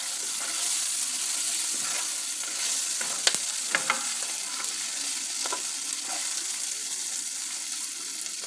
Звуки гриля
Шум мяса на гриле